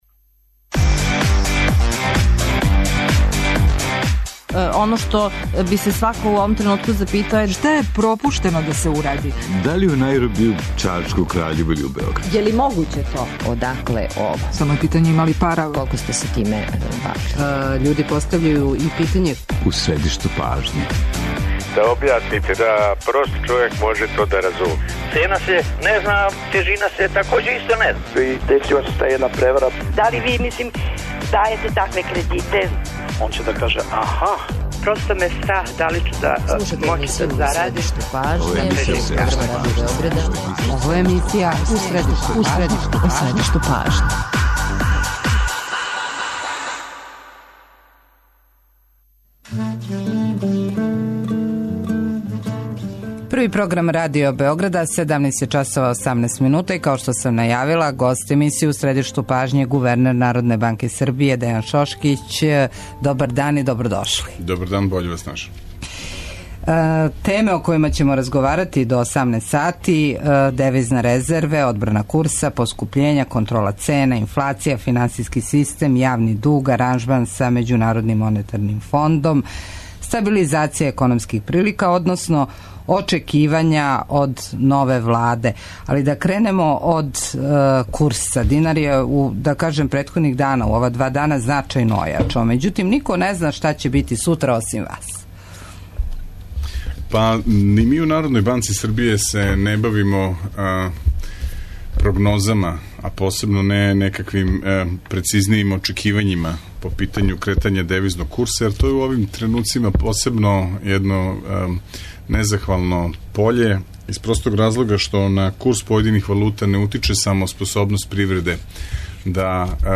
Гост емисије је гувернер Народне банке Србије Дејан Шошкић, а централна тема разговора су девизне резерве и одбрана курса.